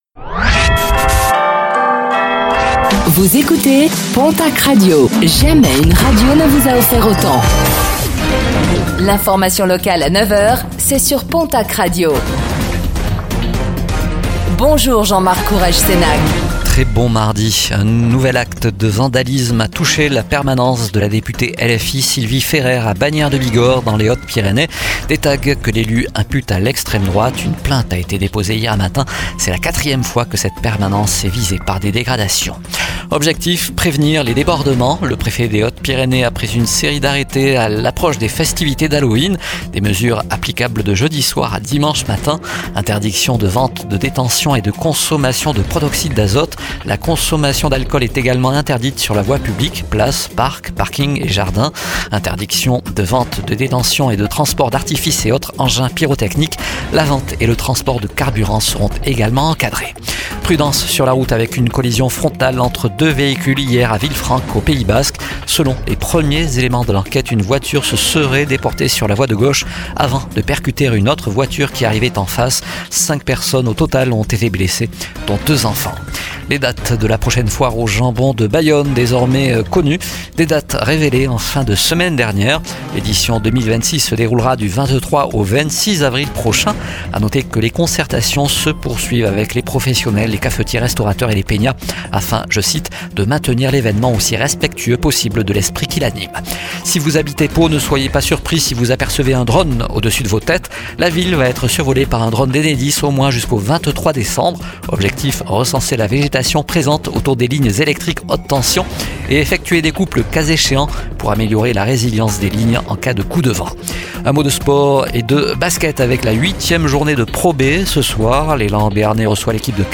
Réécoutez le flash d'information locale de ce mardi 28 octobre 2025